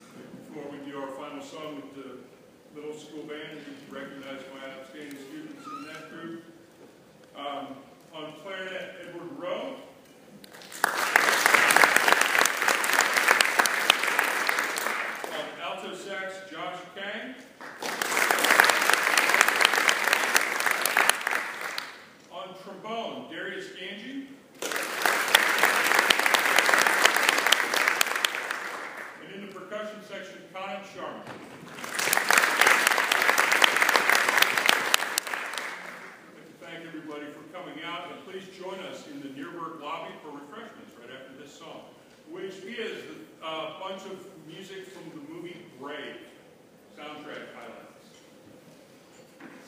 2014 Spring Concert